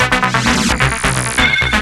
TECHNO125BPM 22.wav